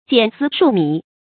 簡絲數米 注音： ㄐㄧㄢˇ ㄙㄧ ㄕㄨˇ ㄇㄧˇ 讀音讀法： 意思解釋： 簡擇絲縷，查點米粒。比喻工作瑣細。